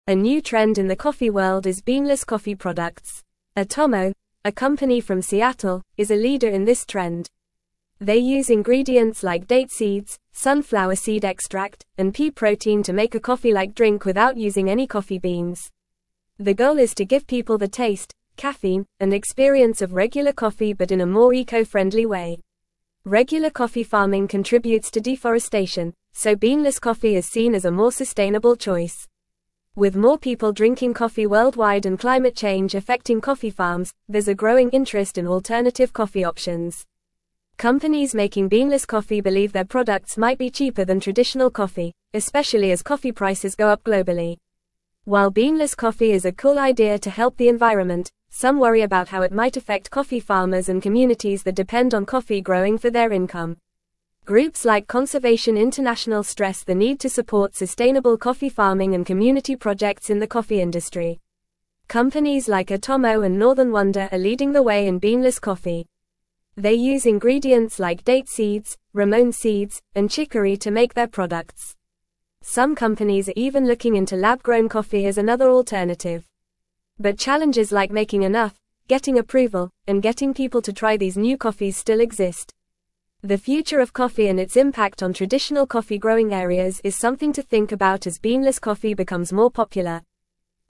Fast
English-Newsroom-Upper-Intermediate-FAST-Reading-Emerging-Trend-Beanless-Coffee-Revolutionizing-the-Industry.mp3